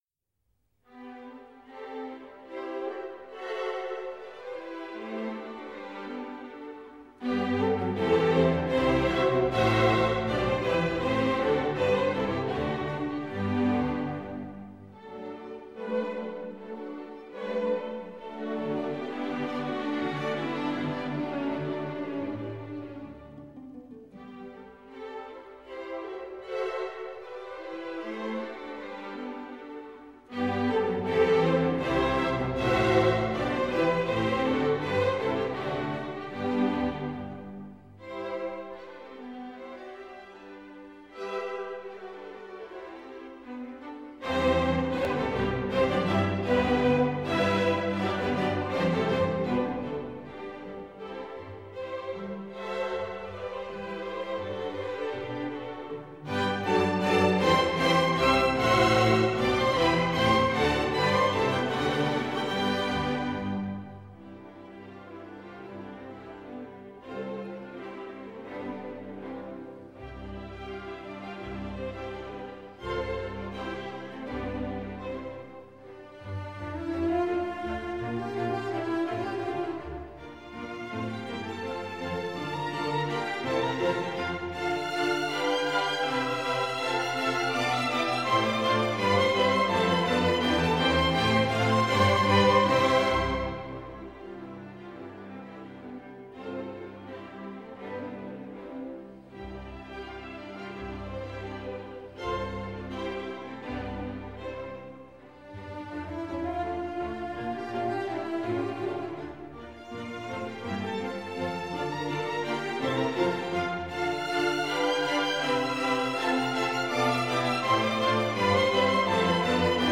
Meditative Musik aus Skandinavien
Wie eine norwegische Fjord-Landschaft glitzern die Melodien im Sonnenlicht und entführen uns in eine Klangwelt voller Zauber und Schönheit.
Schlagworte Gade, Niels W. • Grieg, Edvard • Klassische Musik • Musik-CD • Sibelius, Jean • Skandinavien